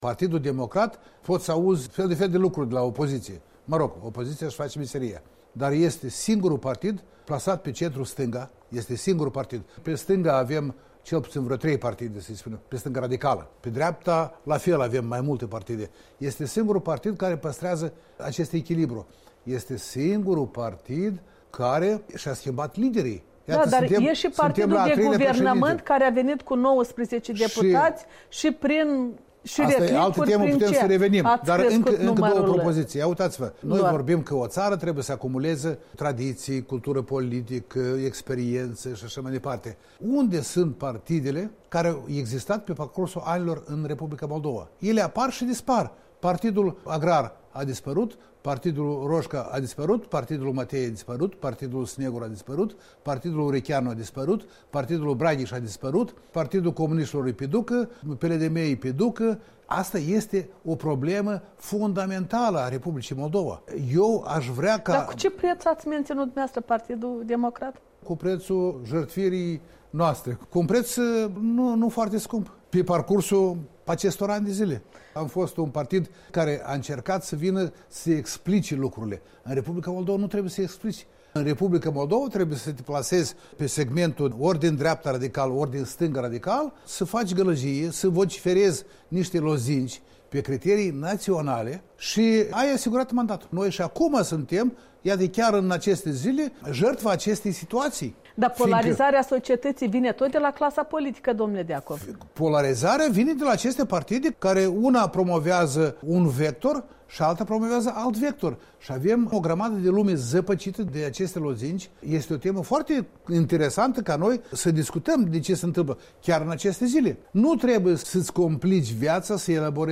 Interviu cu președintele de onoare al Partidului Democrat din Republica Moldova